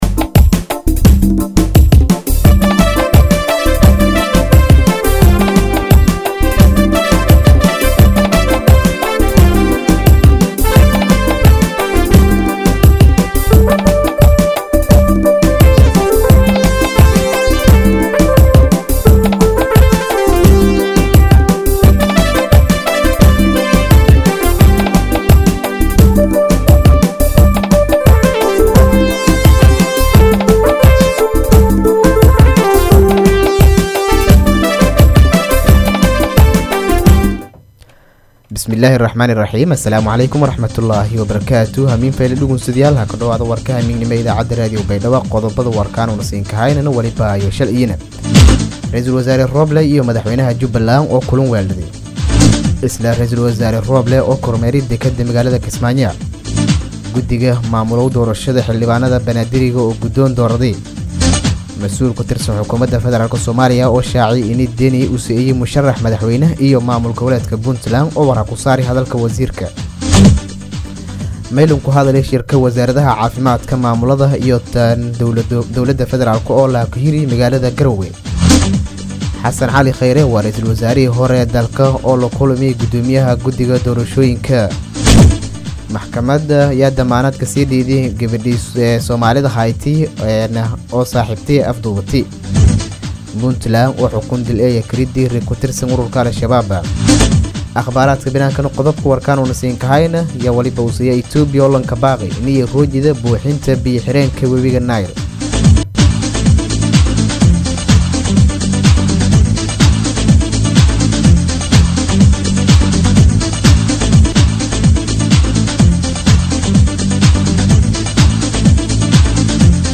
DHAGEYSO:- Warka Habeenimo Radio Baidoa 7-7-2021